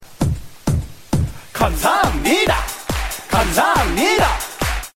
알림음 8_감사합니다감사합니다.ogg